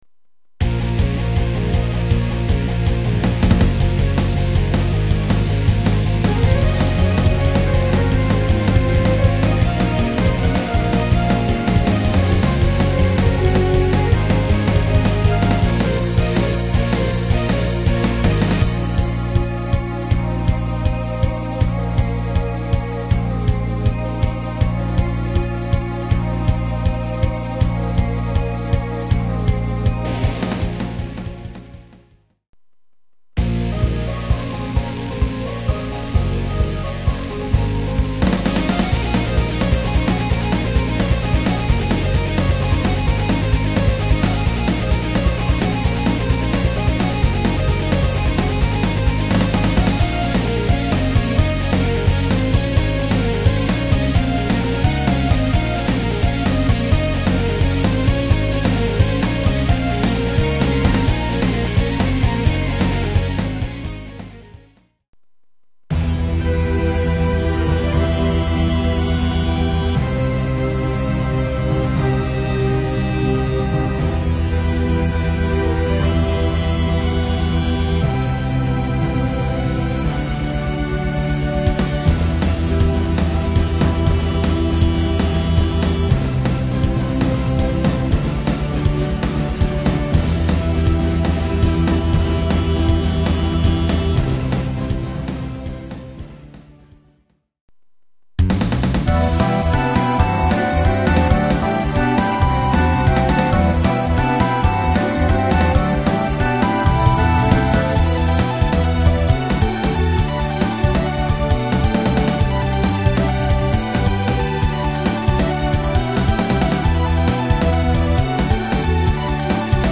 概要 本作品は戦闘シーン、とくに強敵などの決戦をイメージとして制作したオリジナルゲーム音楽集です。
激しいもの、暗い雰囲気の戦闘などを含め、全12曲収録しています。